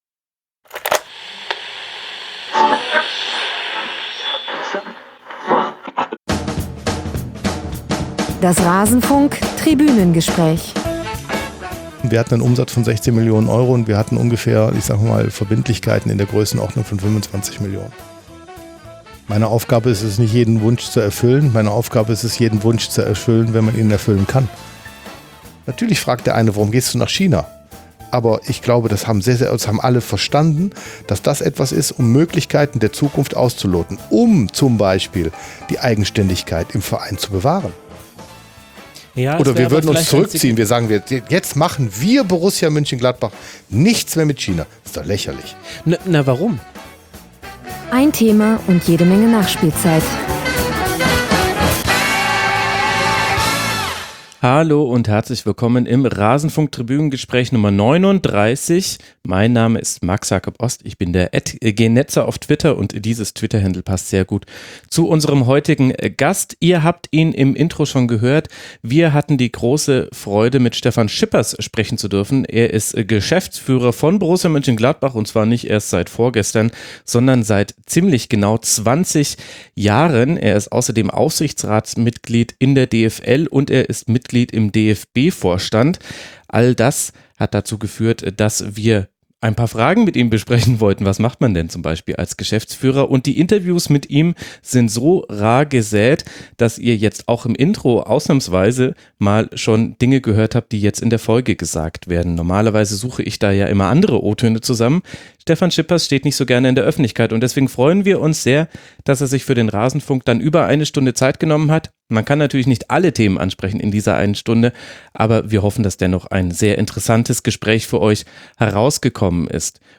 Portrait